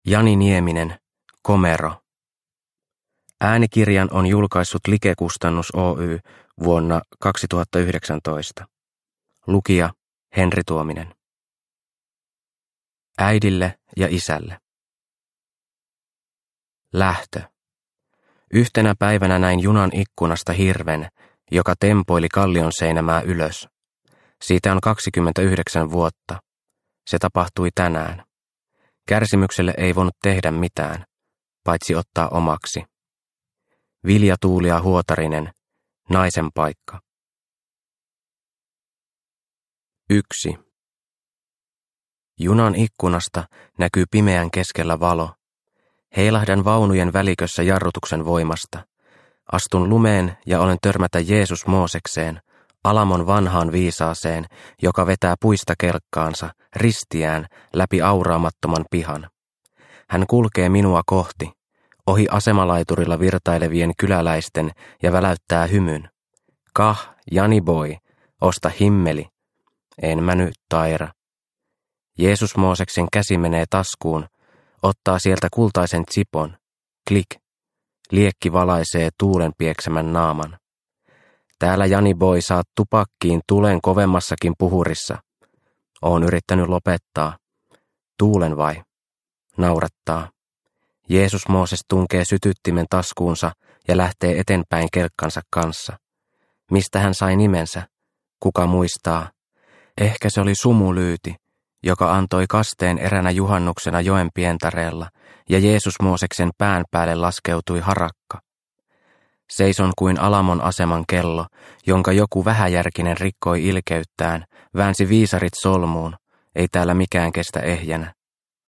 Komero – Ljudbok – Laddas ner